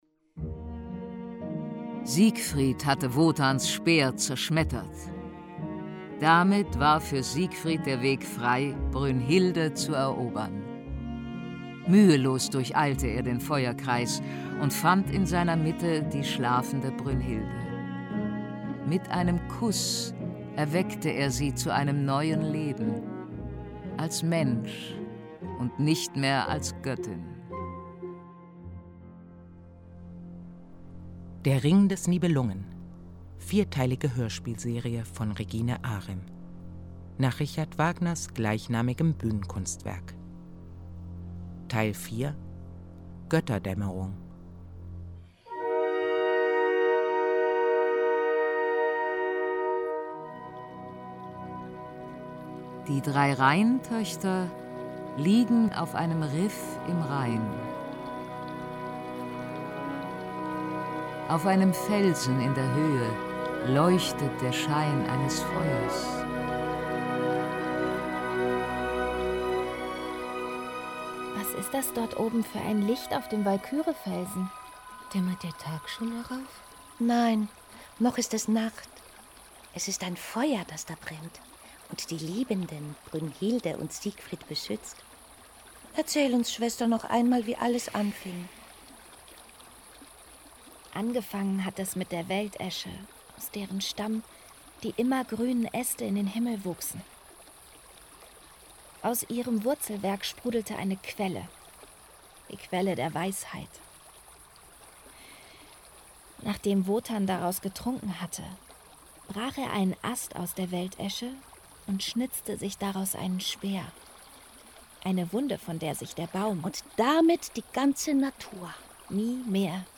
ein Hörspiel